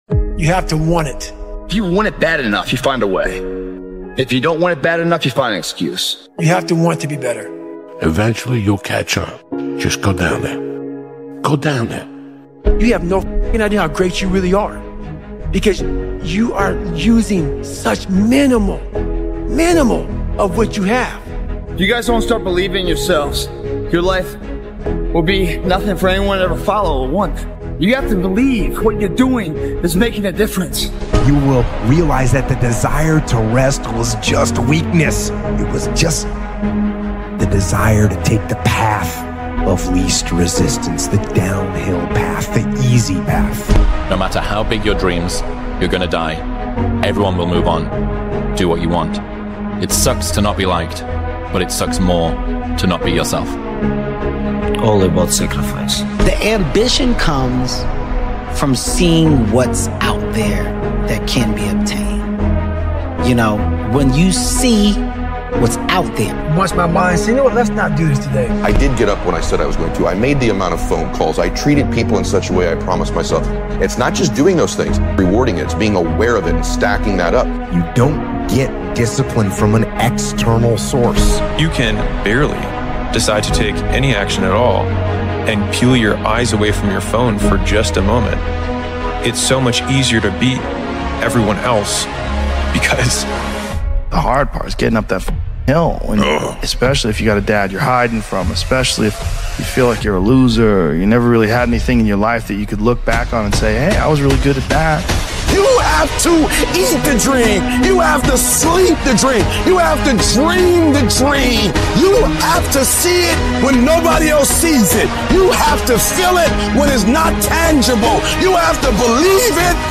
You Have to Want It: Crush Your Limits with This High-Intensity Speech
All ads in Quote of Motivation begin right at the start of each episode so nothing interrupts the moment you settle in, breathe, and feel that familiar spark rising.